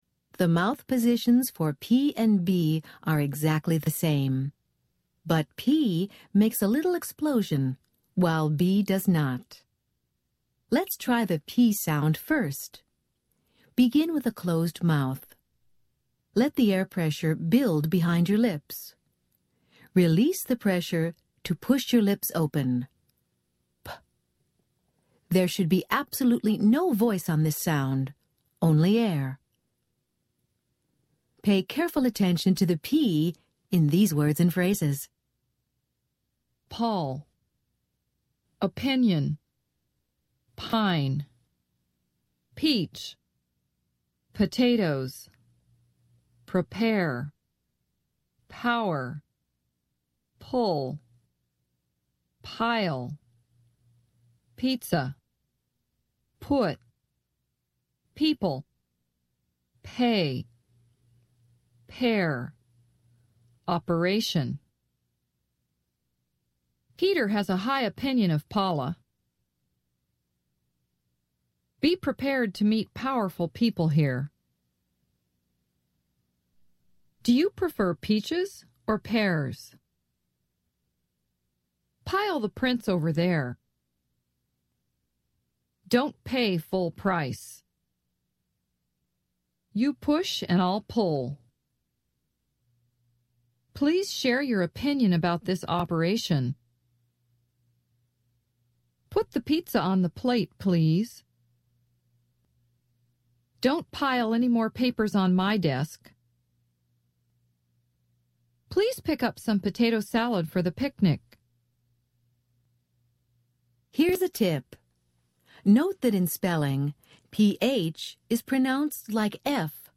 American Phonics Lesson 29 - Consonant /p/